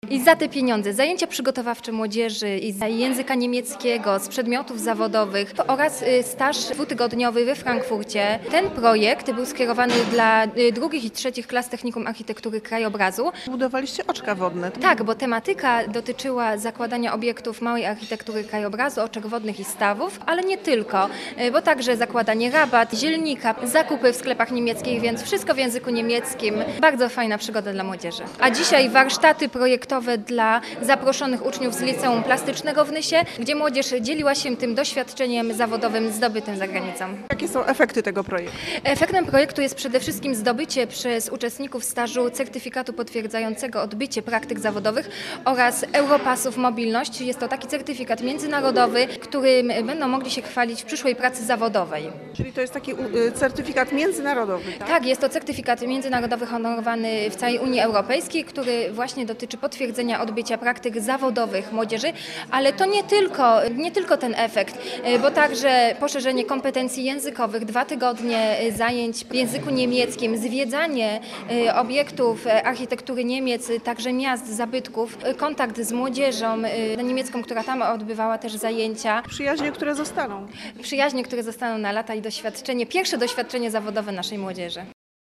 7 listopada 2014 r. – emisja na antenie Radio Opole informacji nt. efektów projektu Leonardo da Vinci oraz odbytej konferencji podsumowującej realizowany projekt
Radio Opole- wywiad